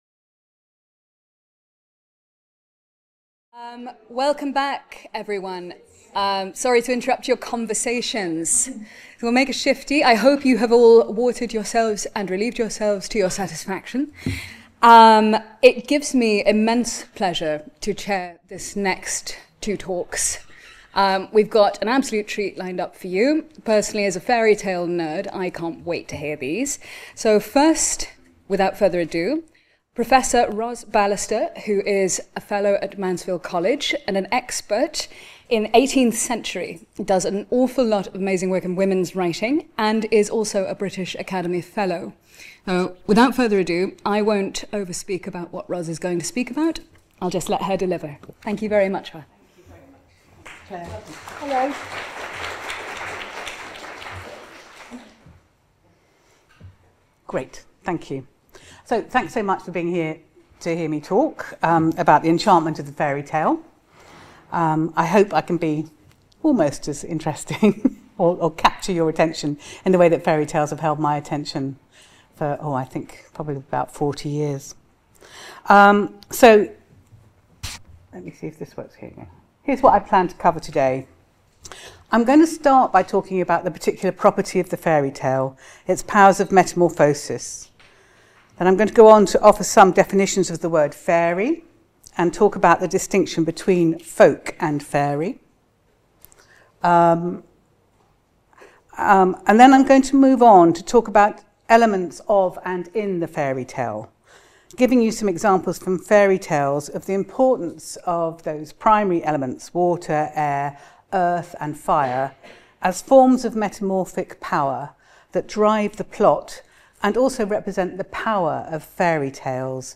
Part of the Bloomsbury-Oxford Summer School (23rd-25th September 2025) held at Exeter College.